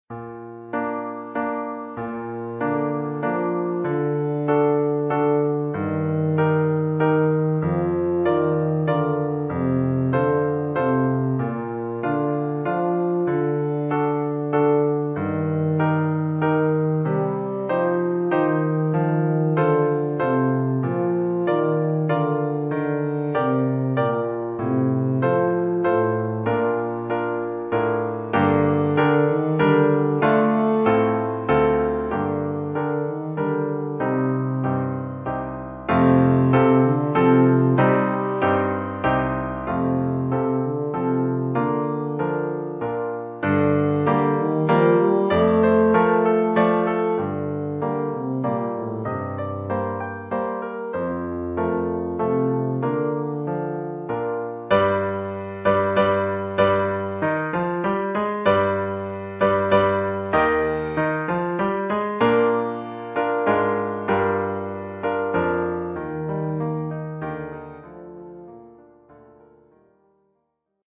Oeuvre pour saxhorn basse /
Niveau : débutant.